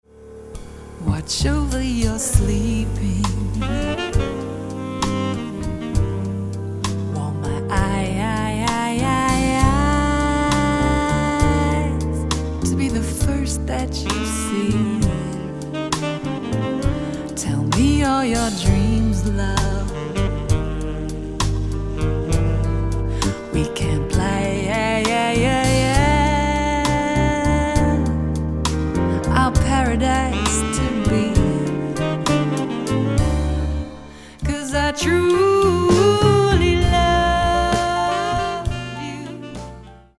This superb album is a blend of Jazz, Latin & Soul.
main vocals & backing backing vocals
tenor & soprano saxes, flute
guitar
piano, percussion
electric & double bass
drums and percussion